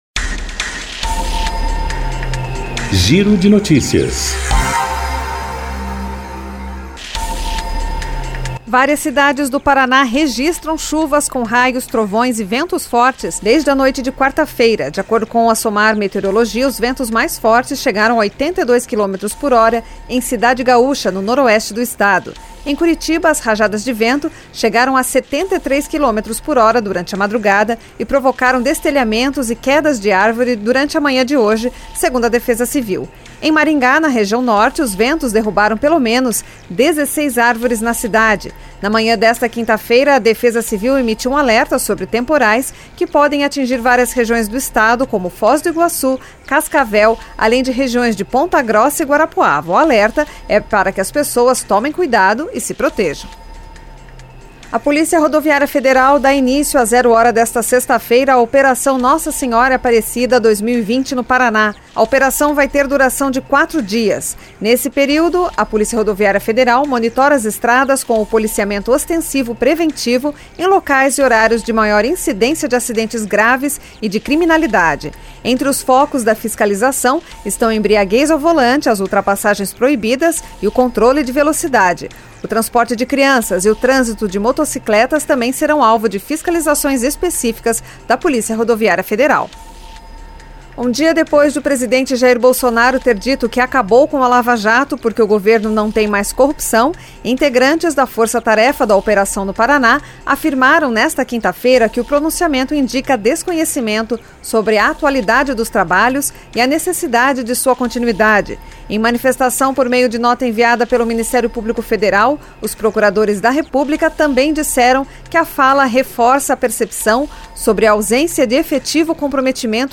Giro de Noticias Tarde COM TRILHA